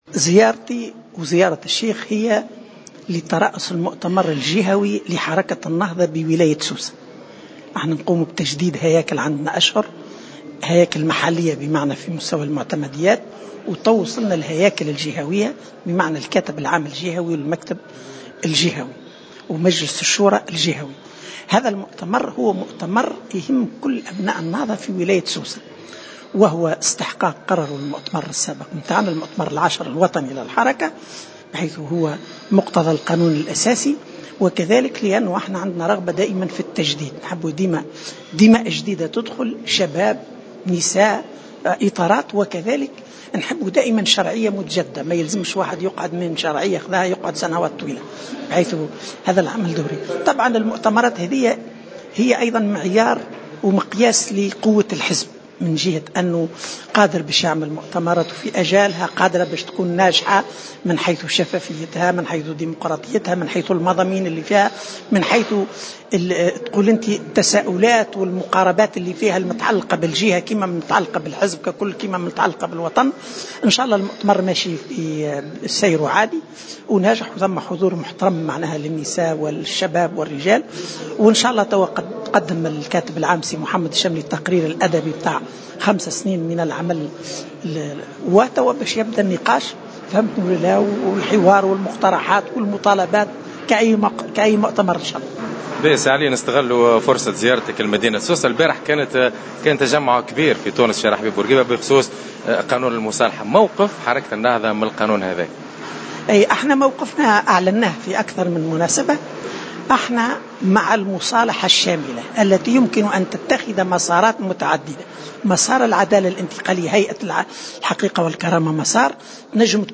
Par ailleurs, il estimé, dans une déclaration accordée à Jawhara Fm, que le projet de la réconciliation présenté par le président de la République, Béji Caïd Essebsi, nécessite des révisions et des amendements fondamentaux pour qu’il puisse réaliser les objectifs escomptés et être en harmonie avec la Constitution et les principes de la justice transitionnelle.